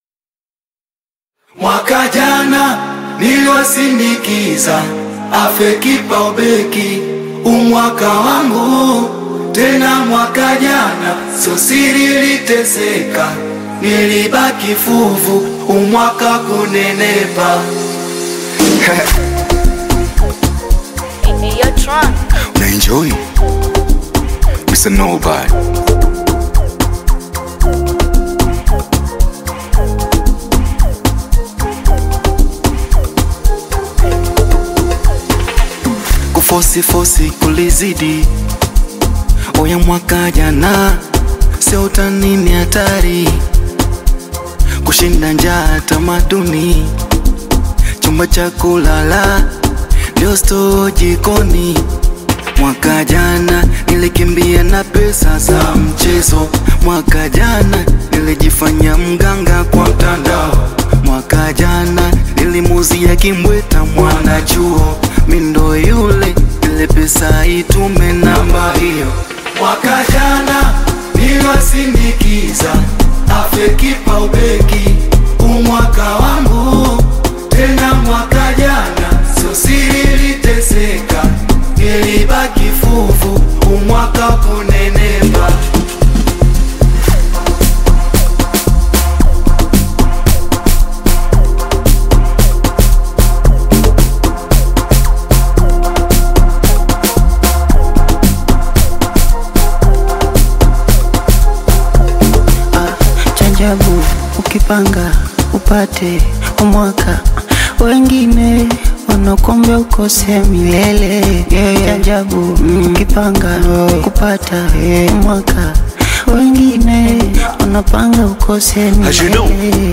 vibrant Afro-Beat single
over catchy melodies and upbeat lyrics
Genre: Amapiano